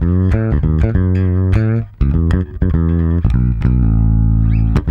-JP MEAN F#.wav